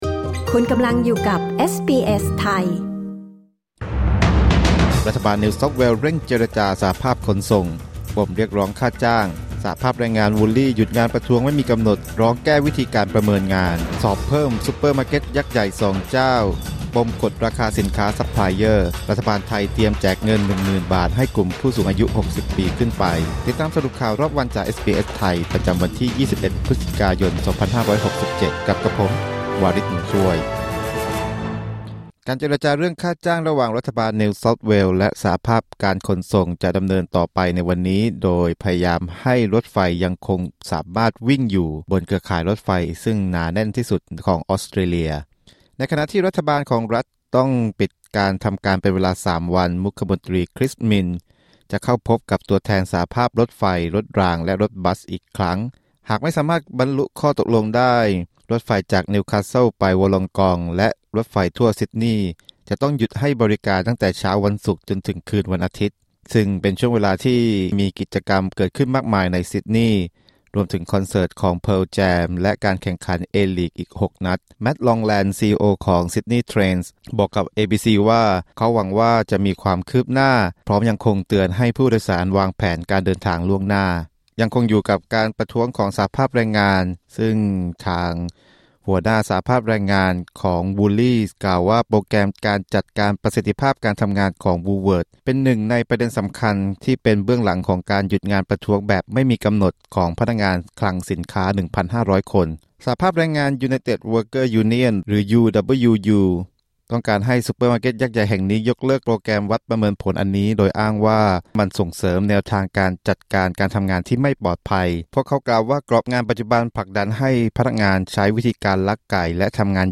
สรุปข่าวรอบวัน 21 พฤศจิกายน 2567